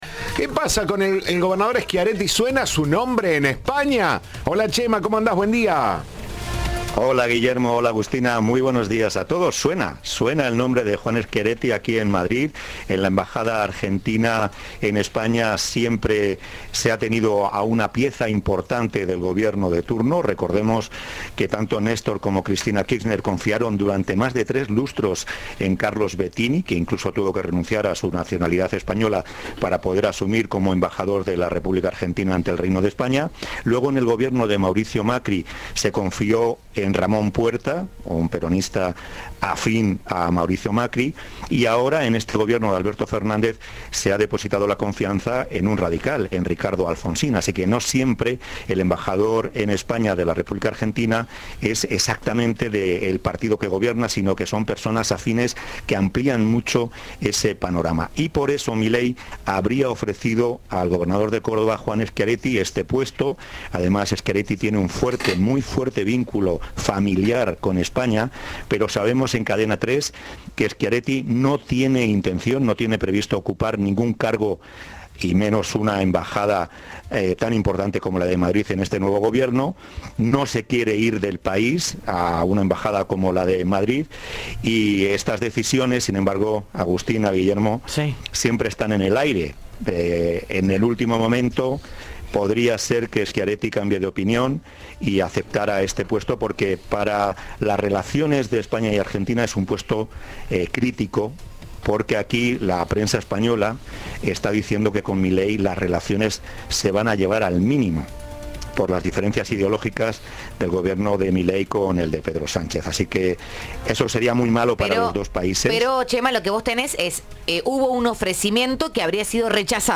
Informe
desde España